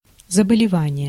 Ääntäminen
France: IPA: [ma.la.di]